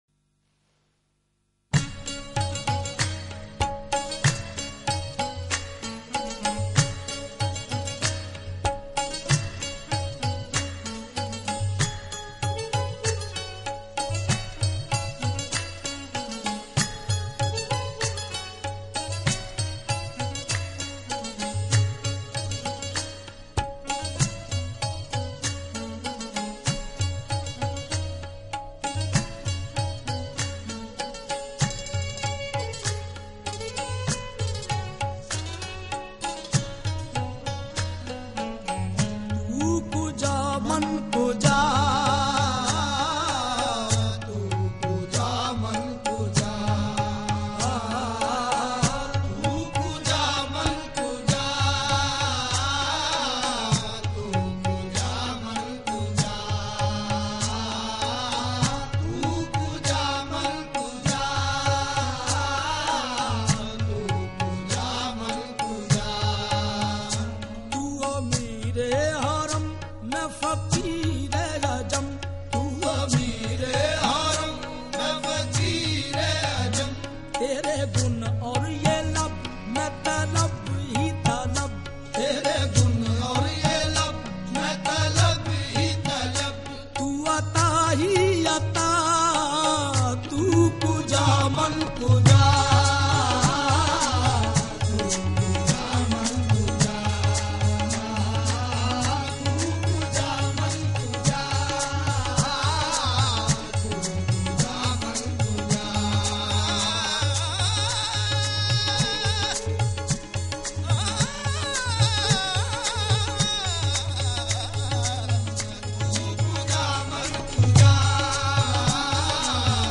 نعت رسول الله